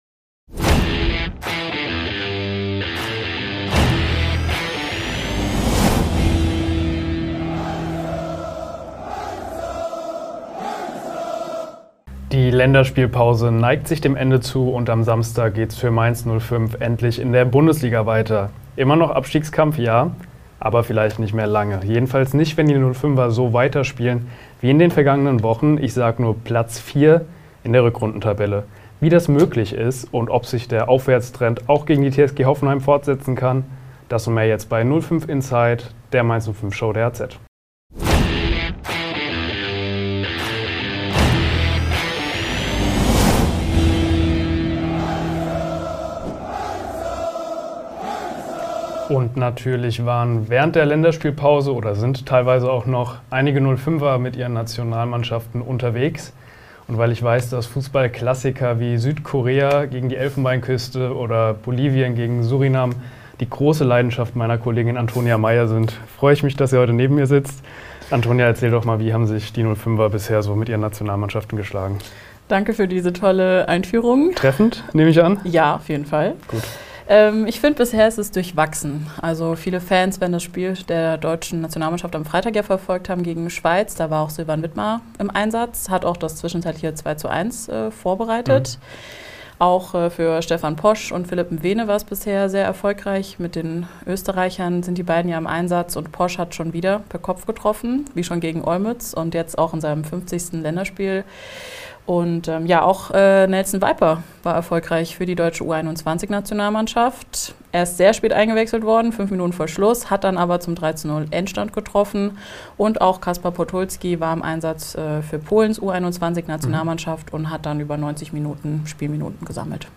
diskutieren die Mainz-05-Reporter